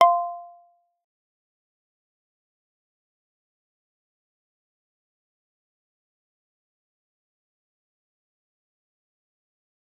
G_Kalimba-F5-f.wav